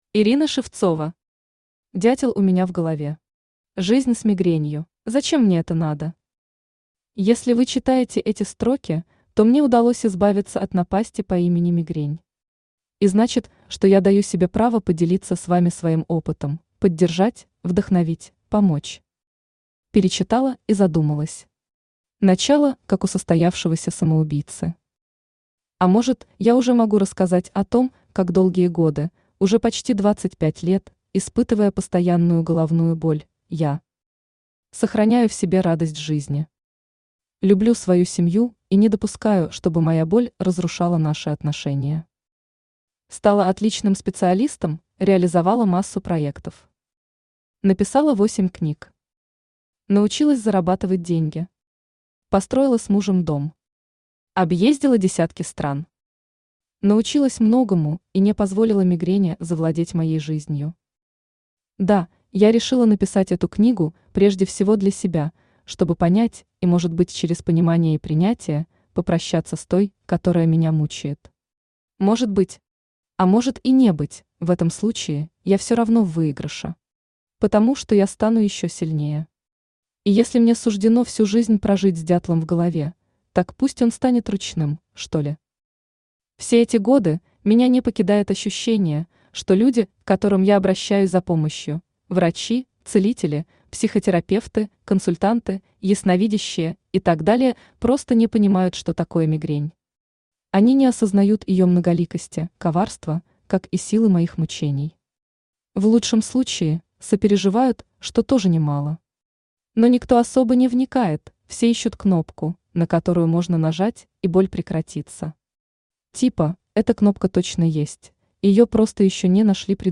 Аудиокнига Дятел у меня в голове. Жизнь с мигренью | Библиотека аудиокниг
Жизнь с мигренью Автор Ирина Шевцова Читает аудиокнигу Авточтец ЛитРес.